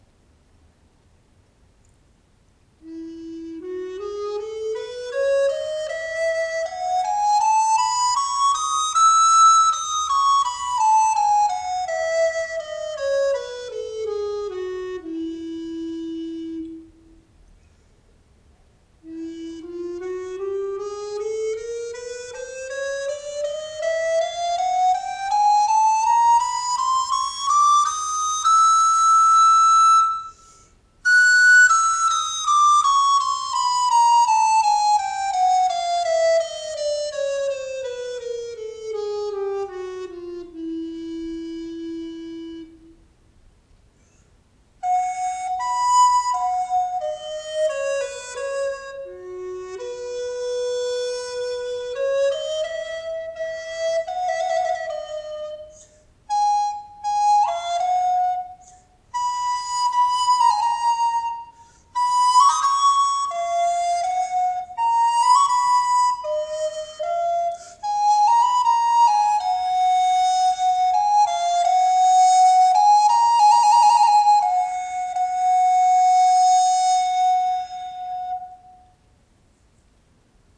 【材質】ブラジル黄楊 染色
比重はボックスウッドよりもやや小さく、音色はボックスウッドに似ていながら、より柔らかく軽い印象です。しかし今回選定した個体は、音に心地よい重厚感が備わっているのがポイント。また音色はフレキシブルで、コントロールできる幅が広いです。